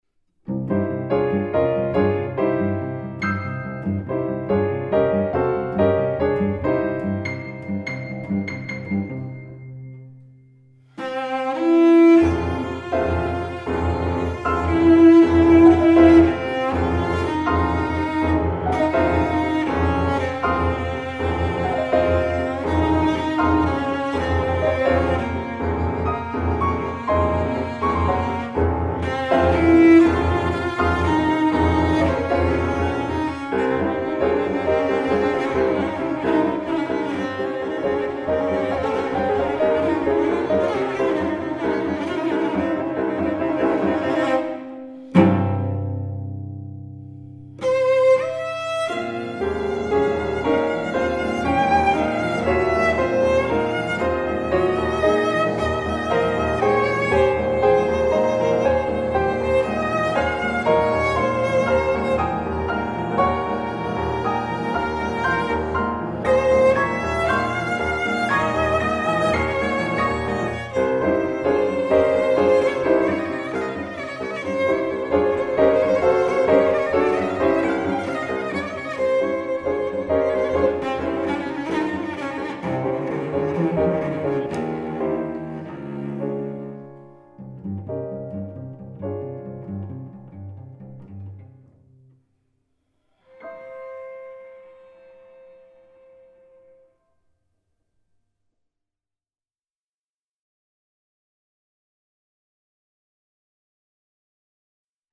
Violoncello
Klavier